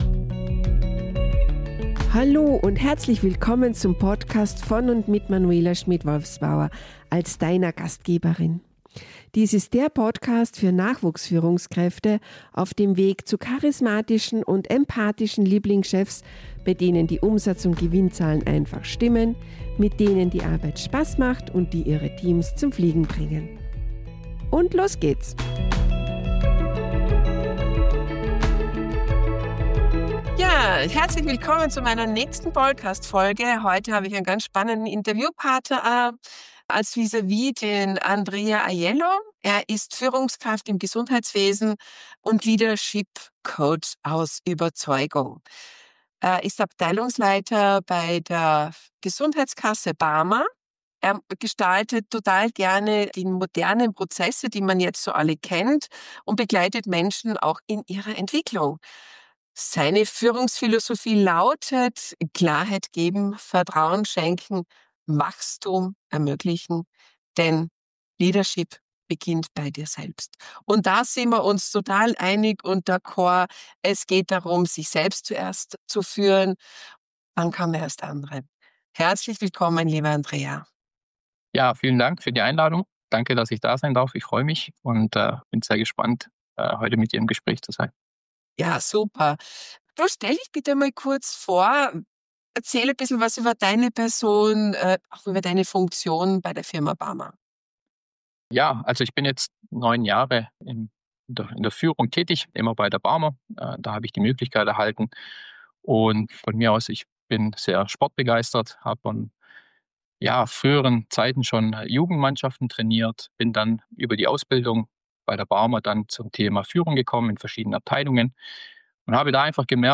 Interview-Folge